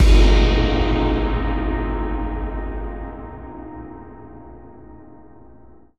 Synth Impact 18.wav